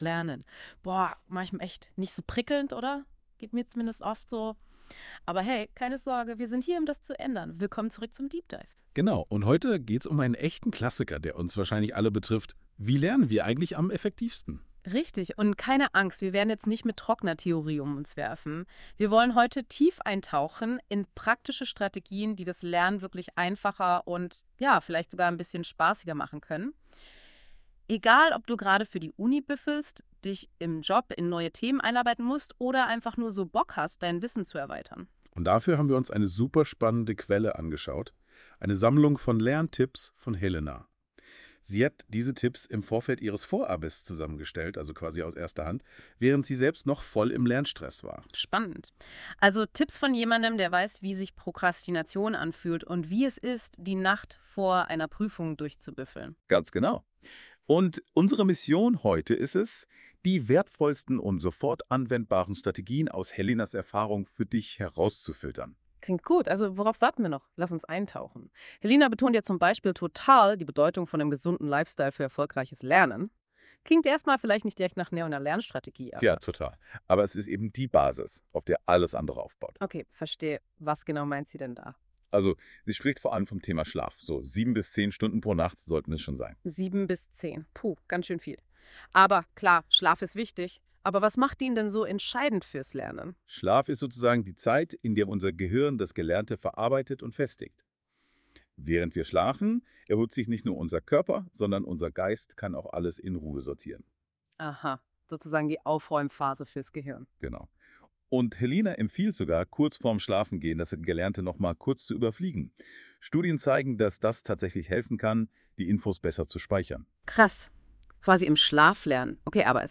Dieder wurde mithilfe einer künstlichen Intelligenz erstellt und ist auch über das Handy abspielbar!